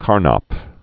(kärnäp, -năp), Rudolf 1891-1970.